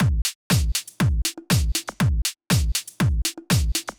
Drumloop 120bpm 08-A.wav